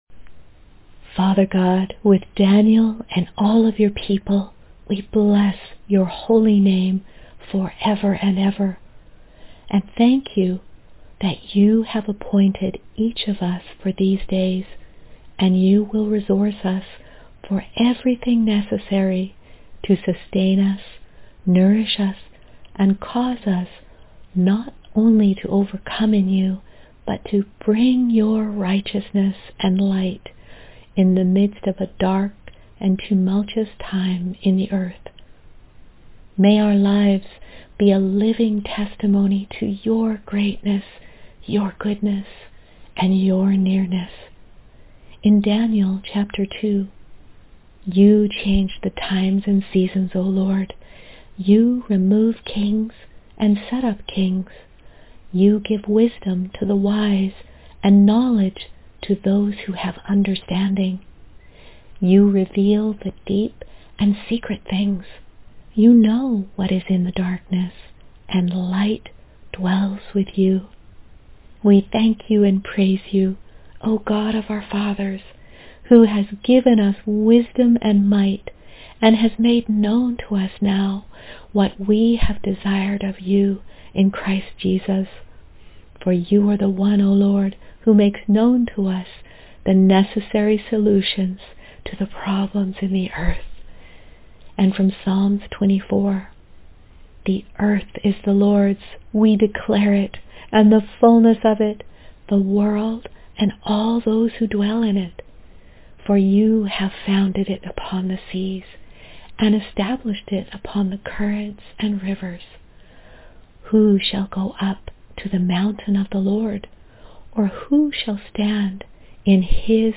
Prayer — Listen Online: